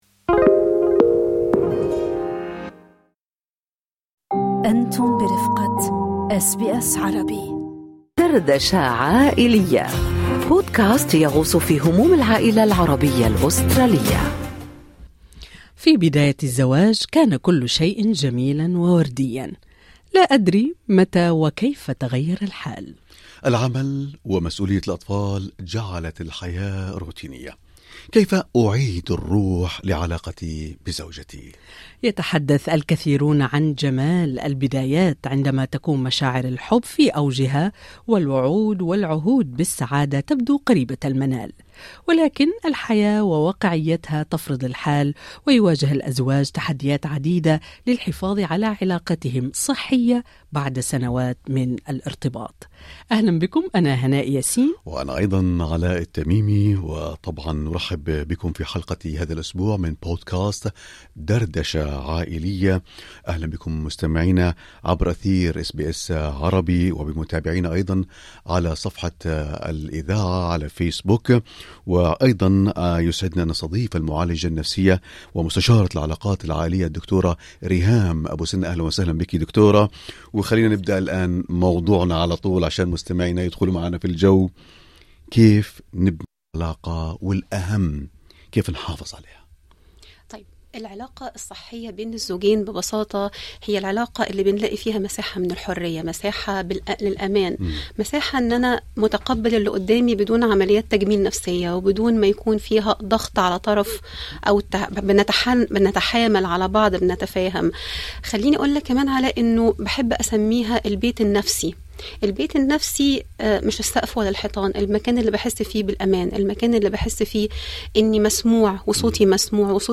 دردشة عائلية: كيف نبني علاقة زوجية صحية ونحافظ عليها؟ معالجة نفسية تجيب